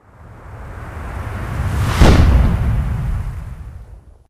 gravi_blowout4.ogg